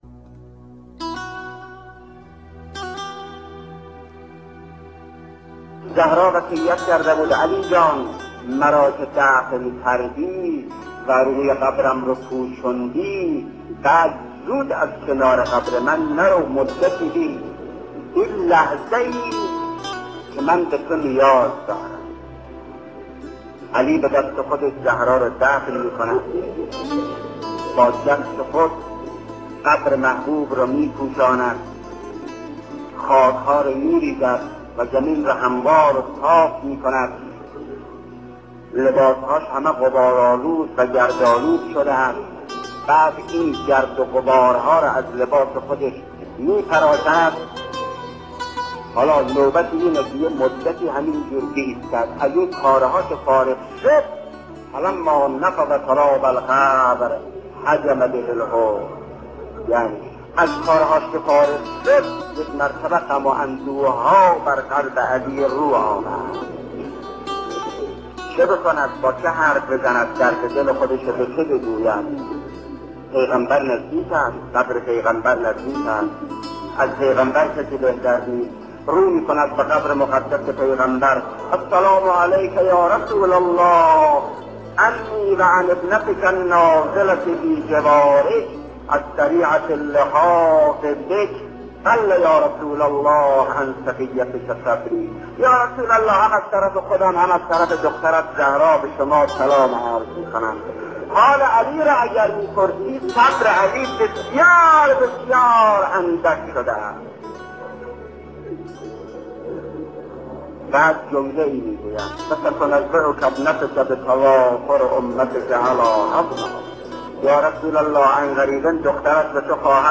صوت/ ذکر مصائب حضرت زهرا(س) با صدای شهید مطهری
برچسب ها: فاطمیه ، شهادت حضرت زهرا ، حضرت زهرا ، بانوی بی نشان ، شهید مطهری ، روضه ، ایکنا ، خبرگزاری بین المللی قرآن